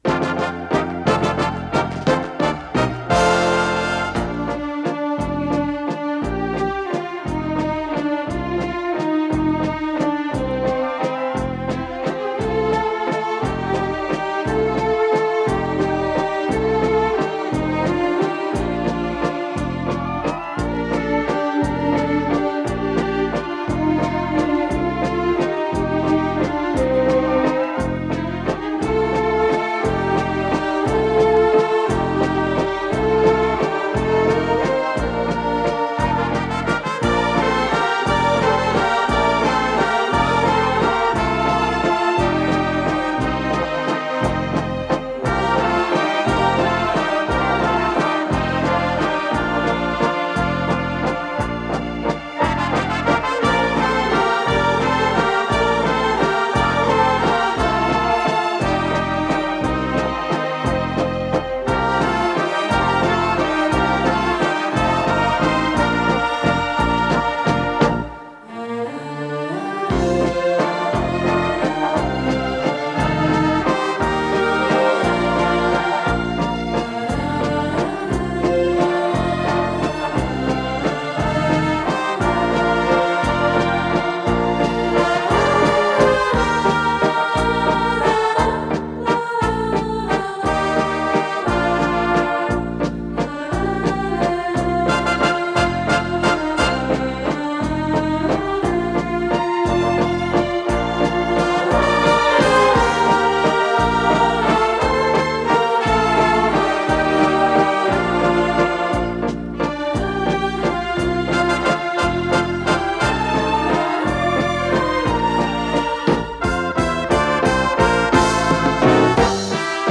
с профессиональной магнитной ленты
фа мажор
ВариантДубль моно